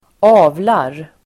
Uttal: [²'a:vlar]